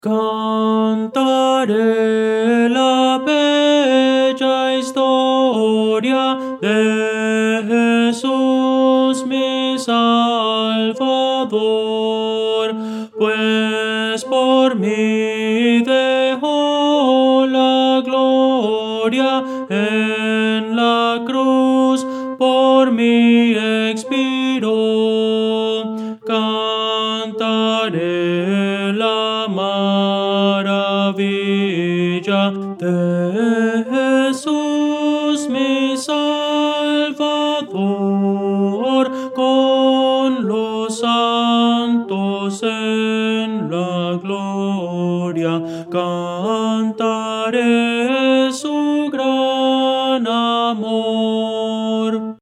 Voces para coro
Tenor – Descargar